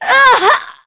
Aselica-Vox_Damage_03.wav